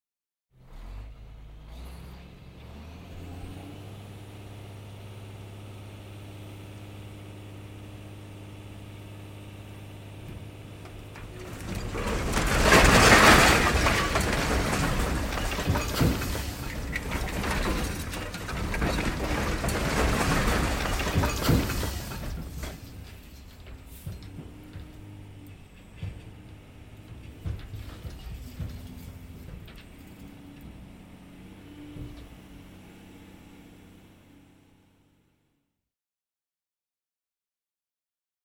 Звуки грузовика, фуры
Самосвал разгружается поднимает кузов высыпает содержимое